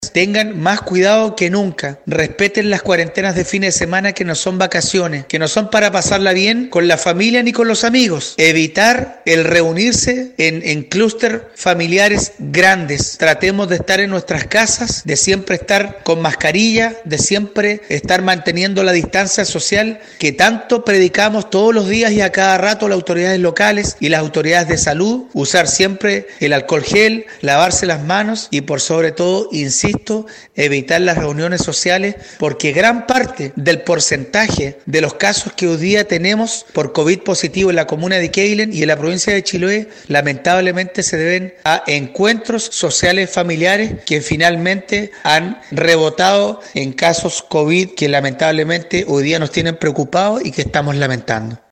El alcalde Marcos Vargas realizó una sentida advertencia a la comunidad, que persiste en “reunirse con otros grupos familiares”, para pasar los días de confinamiento.